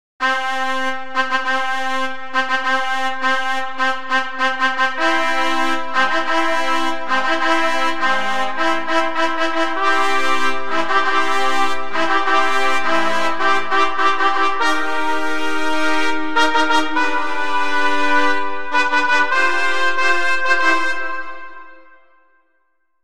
trumpet fanfare